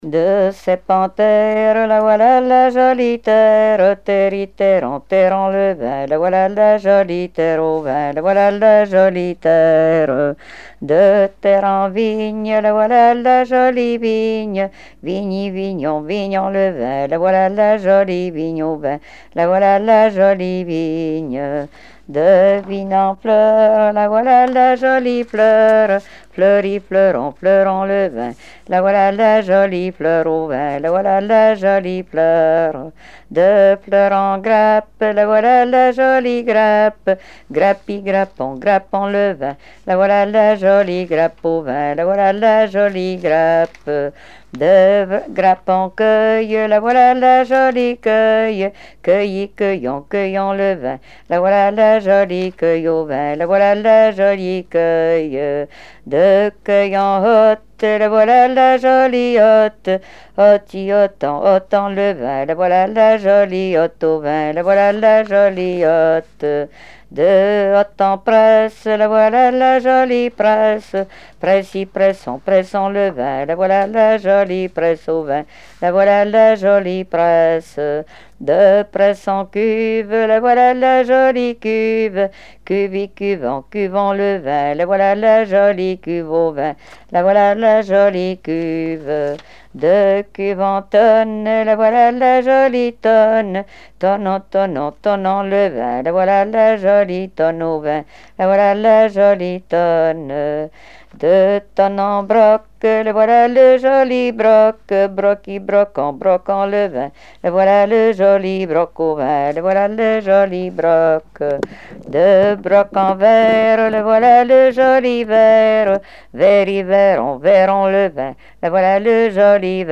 Genre énumérative
Répertoire de chansons traditionnelles et populaires